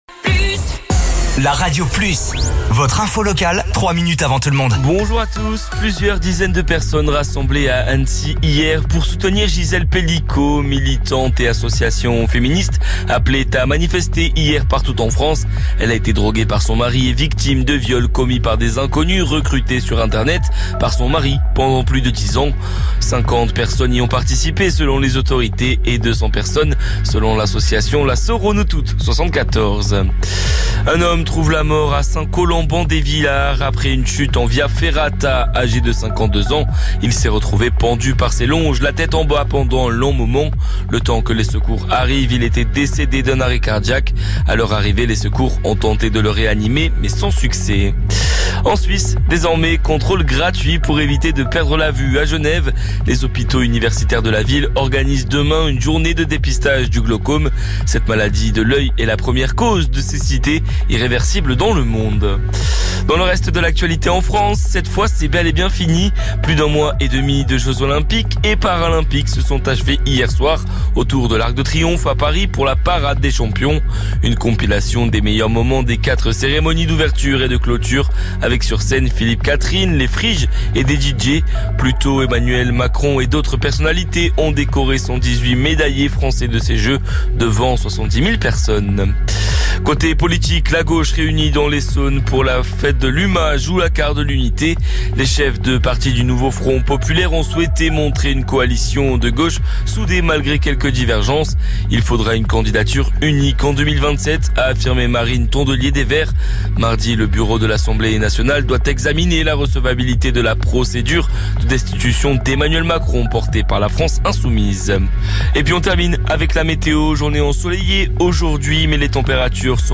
Flash Info
Votre flash info - votre journal d'information sur La Radio Plus